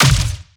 Plasma Rifle
LASRGun_Plasma Rifle Fire_03_SFRMS_SCIWPNS.wav